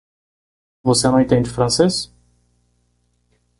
Pronúnciase como (IPA)
/fɾɐ̃ˈse(j)s/